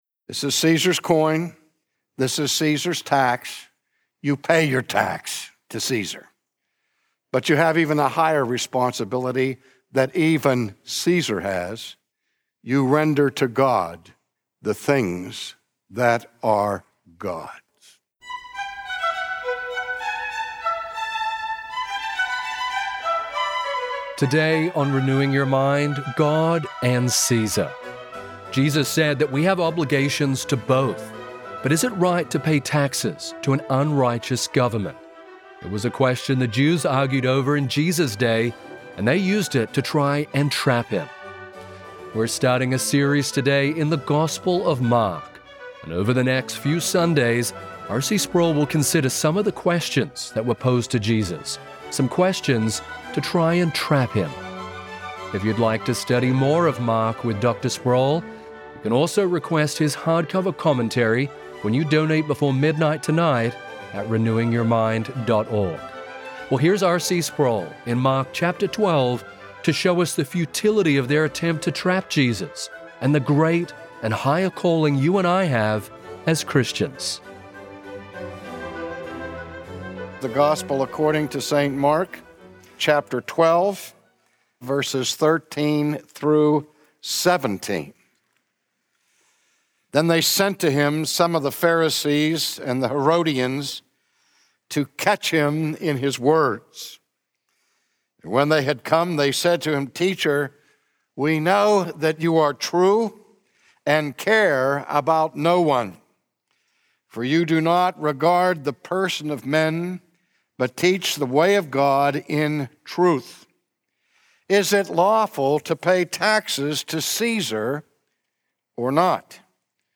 Should Christians pay taxes to wicked rulers? From his sermon series in the gospel of Mark